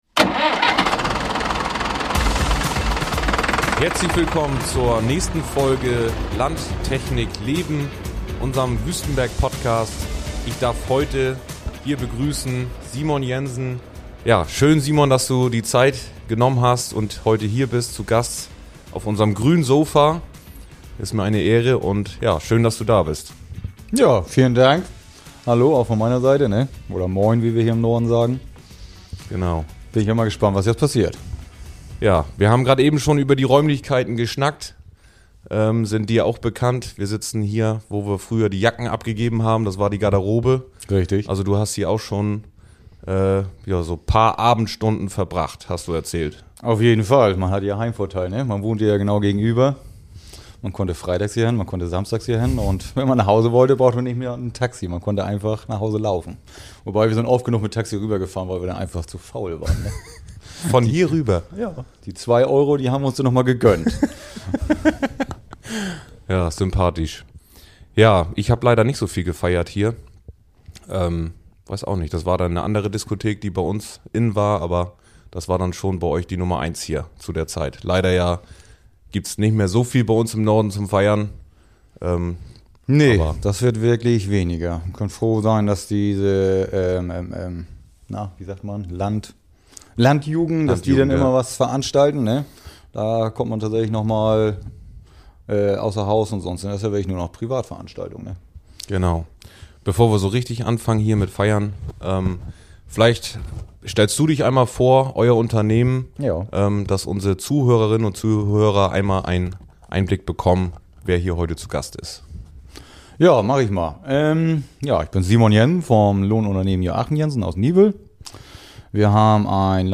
Gemeinsam sprechen die beiden über den Alltag in der Ernte, über Teamarbeit, Technik und darüber, wie sich das Lohnunternehmer-Dasein in den letzten Jahren verändert hat.